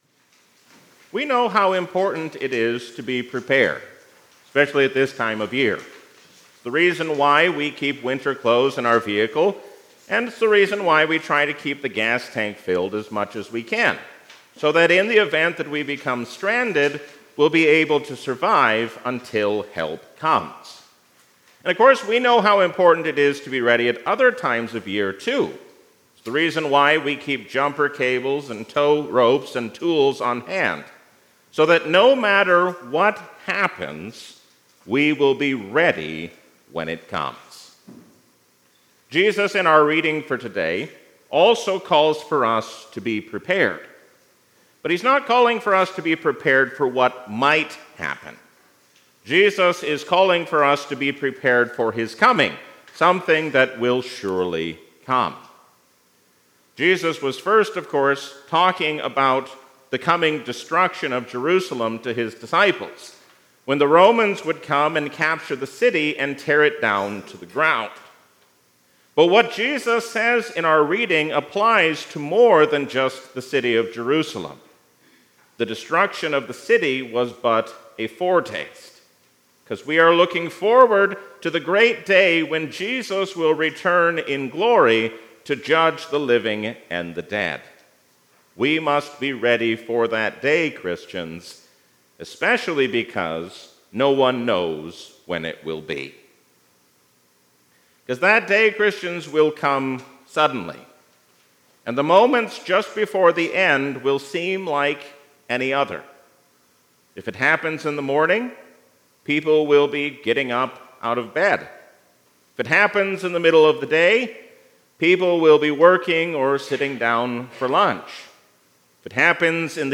A sermon from the season "Advent 2025." True repentance means that God moves us to turn away from our sin.